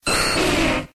Cri d'Herbizarre dans Pokémon X et Y.